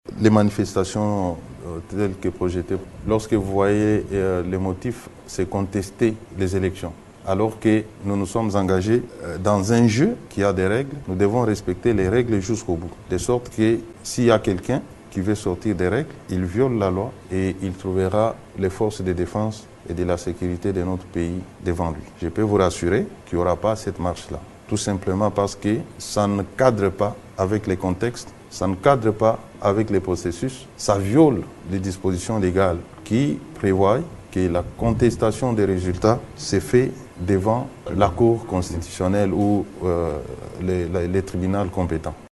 Peter Kazadi l’a indiqué lors du briefing du Gouvernement sur la situation générale dans le pays après les élections du 20 décembre courant.